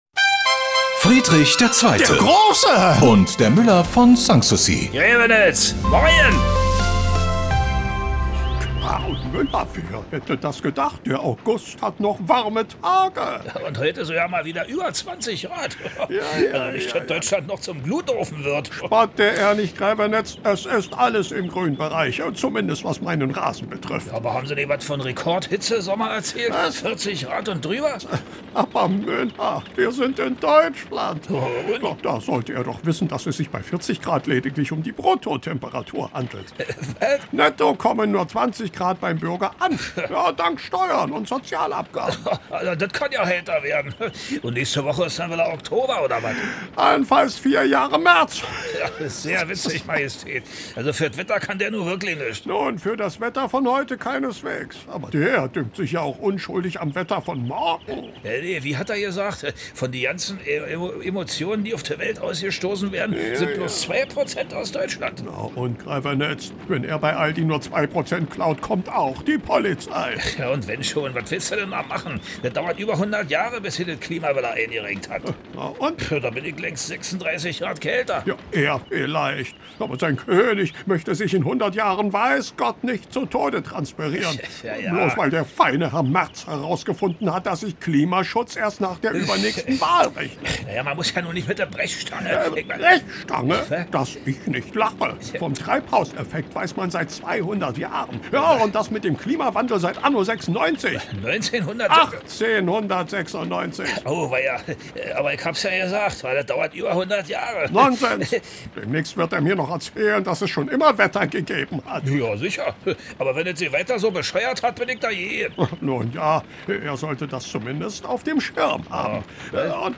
… continue reading 24 Episoden # Komödie # Antenne Brandenburg, Rundfunk berlin-Brandenburg, Germany # Antenne Brandenburg # Rundfunk Berlin-brandenburg